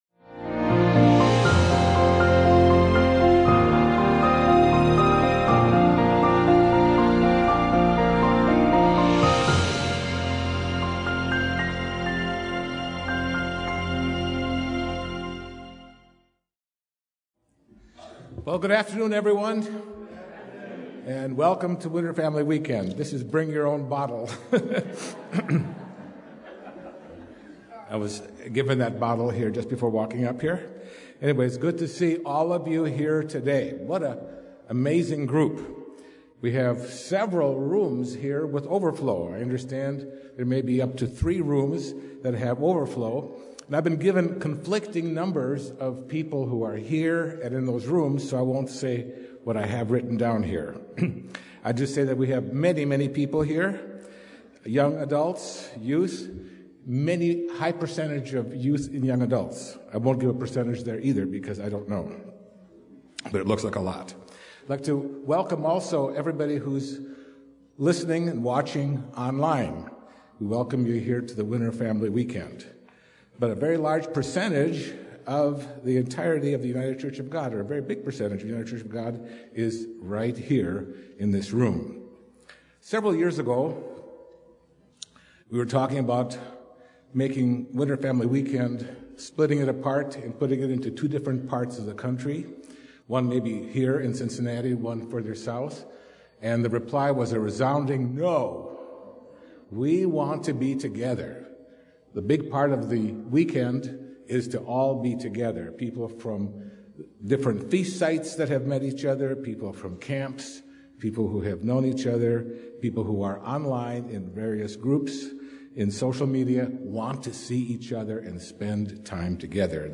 This sermon focuses on practical applications of how we can sharpen others and also how we can be sharpened, no matter our age. We can sharpen others through fellowship and encouragement. We can ourselves be sharpened by being among diverse, different people and through feedback.